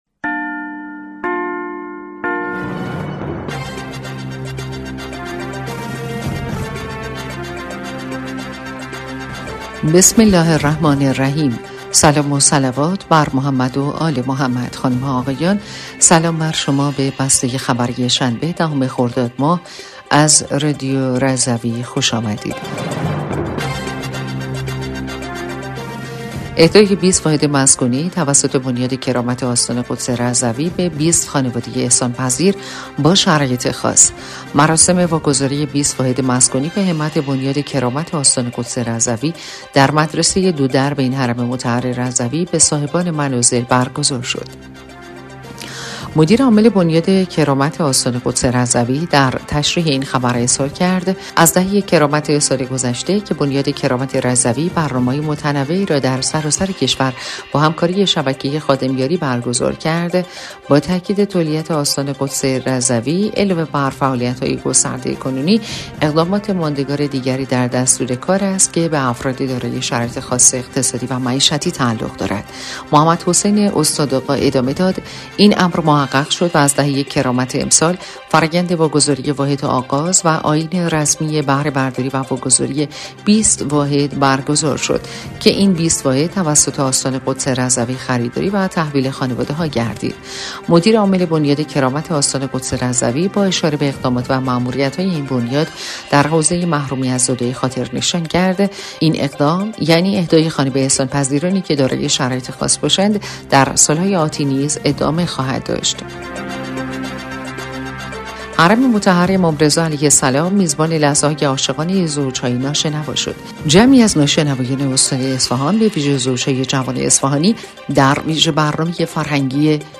بسته خبری شنبه ۱۰ خردادماه رادیو رضوی/